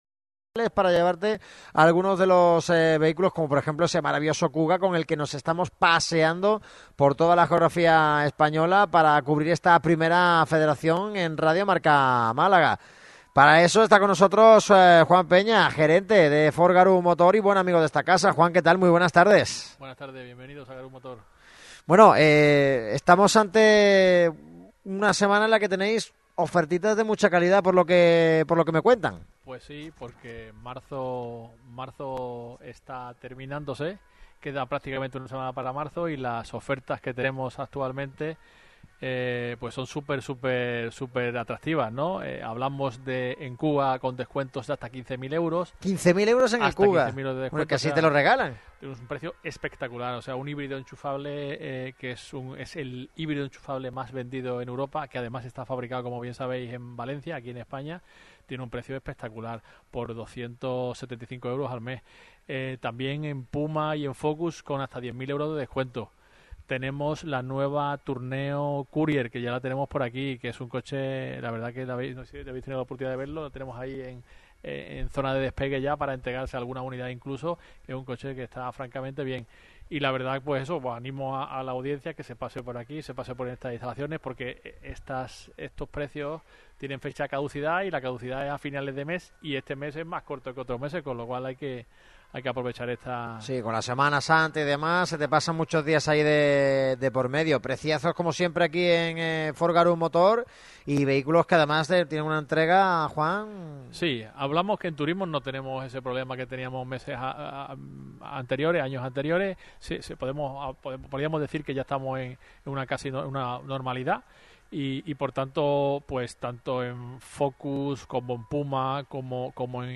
Radio Marca Málaga vuelve una vez más a Ford Garum Motor, en la avenida Luis XXIII, concesionario oficial de la marca estadounidense en la capital costasoleña, de los miembros por excelencia en la familia de la radio del deporte.